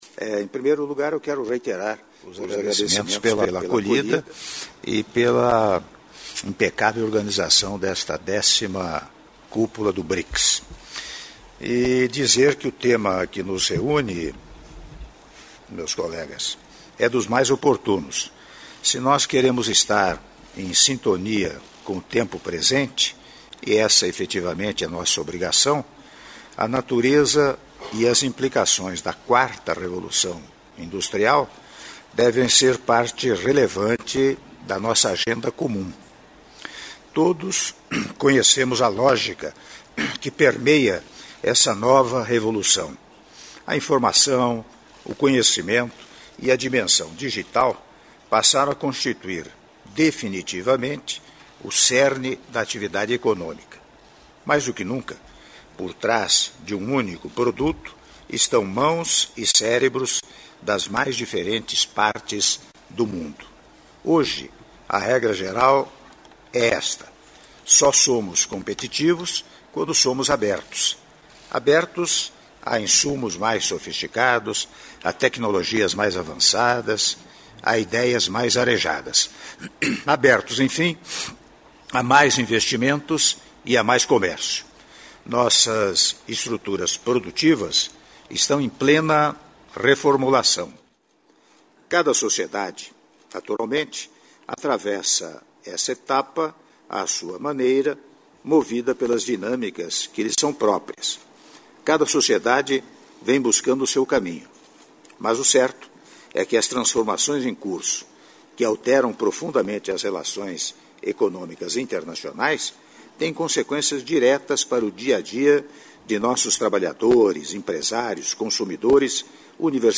Áudio do discurso do Presidente da República, Michel Temer, durante Reunião Plenária Aberta dos Chefes de Estado e de Governo do Brics, 1ª Sessão - Joanesburgo/África do Sul- (06min42s)